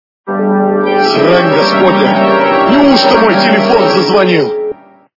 » Звуки » Люди фразы » Голос - Срань господня, неужто мой телефон зазвонил
При прослушивании Голос - Срань господня, неужто мой телефон зазвонил качество понижено и присутствуют гудки.